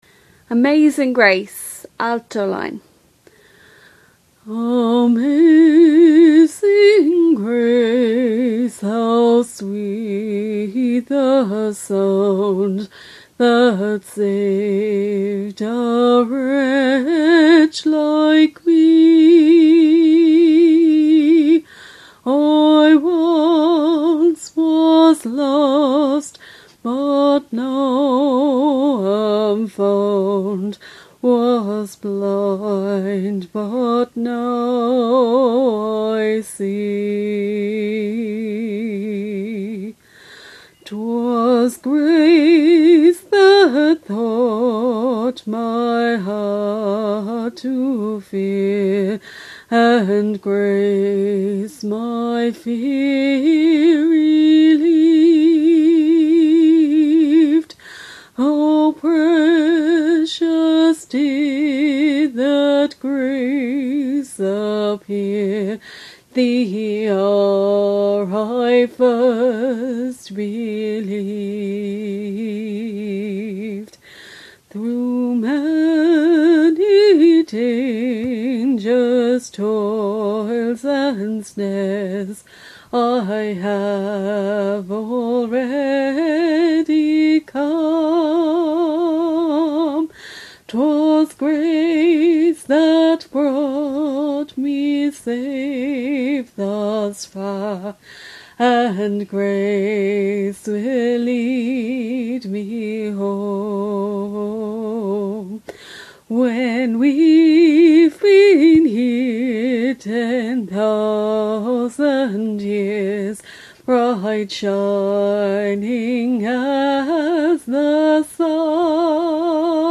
Simple Harmonies
Harmony
AmazingGrace-alto-.mp3